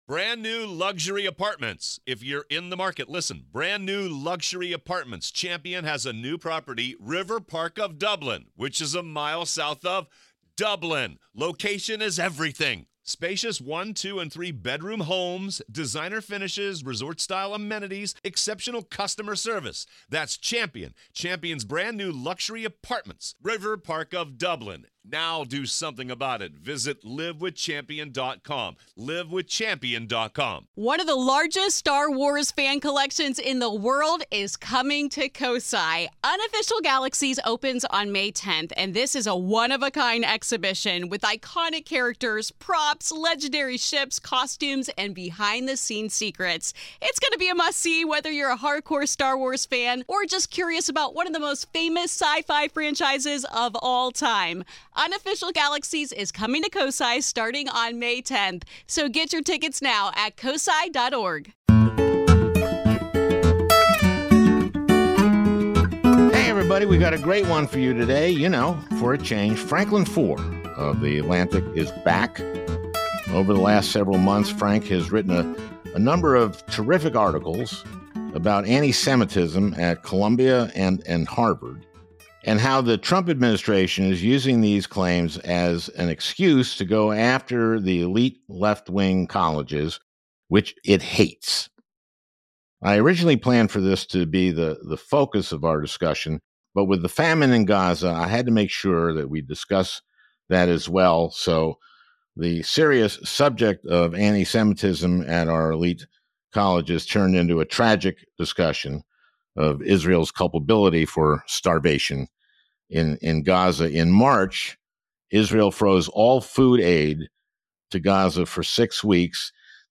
Gaza faces mass starvation as Israel blocks critical food and aid to the region. We're joined by The Atlantic's Franklin Foer to discuss his recent piece, “Israel's Last Chance,” and how the only answer to this atrocity is for Israel to flood Gaza with food.